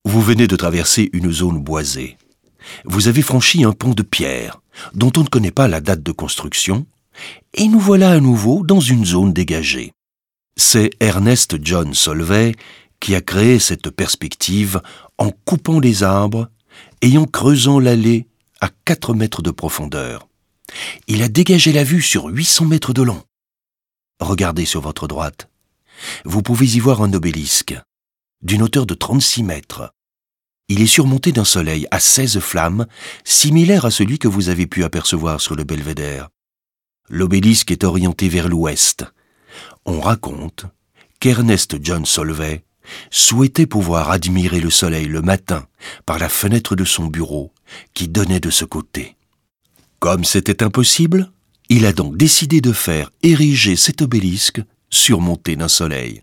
la_hulpe_audioguide_adulte_partie_06.mp3